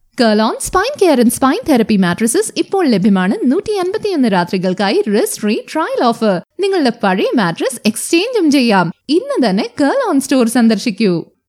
Female
Hindi Radio Commercial
Radio Commercials